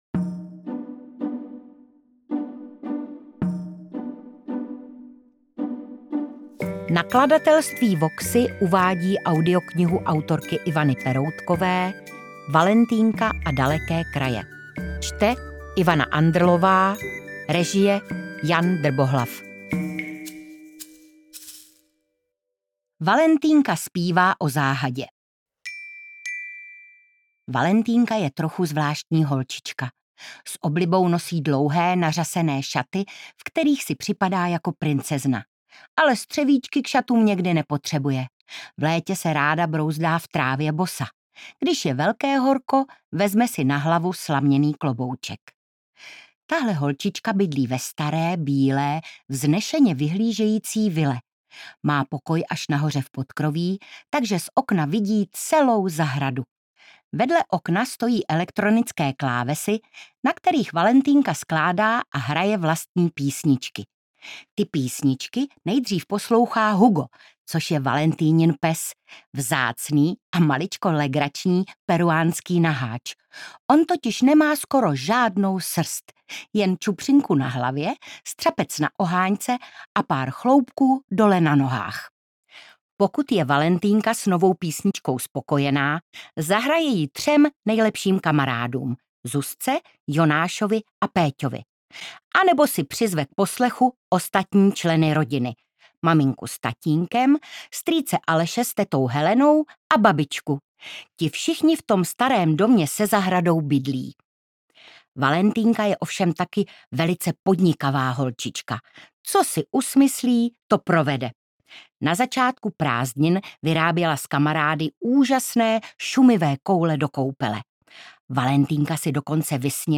Čte: Ivana Andrlová
audiokniha_valentynka_a_daleke_kraje_ukazka.mp3